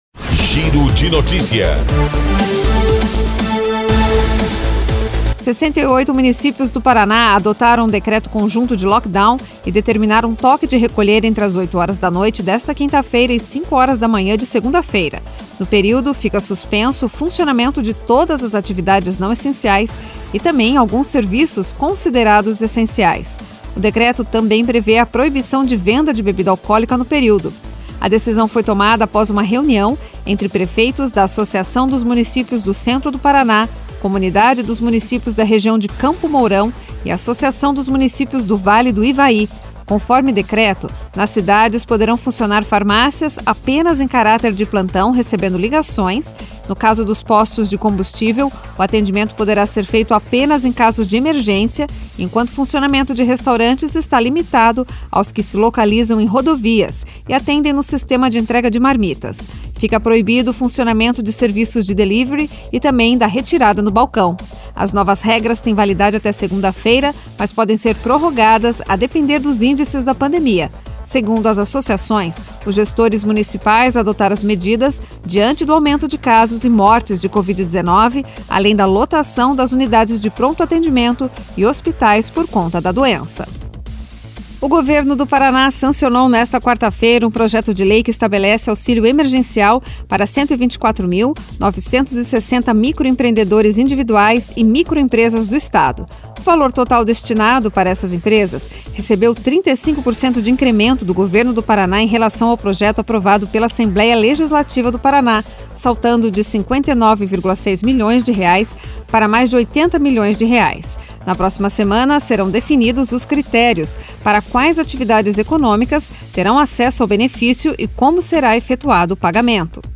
Giro de Noticias Manhã COM TRILHA